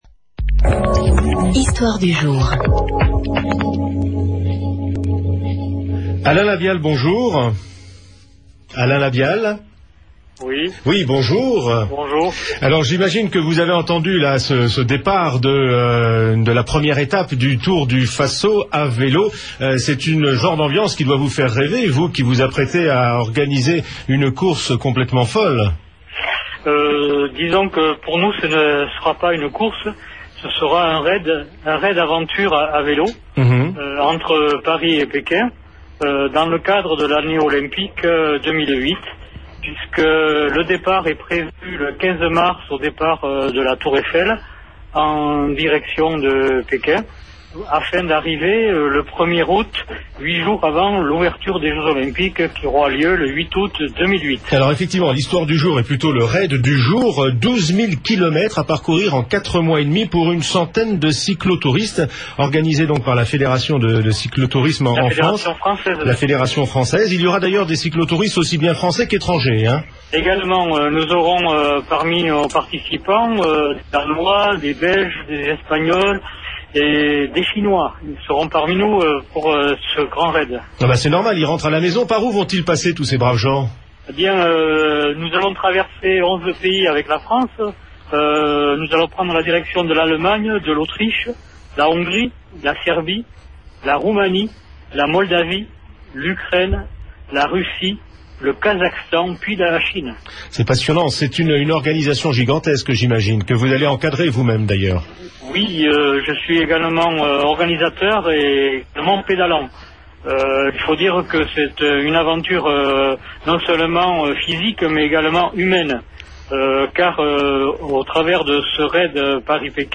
RFI, interview, 14 décembre 2006 (3 MO)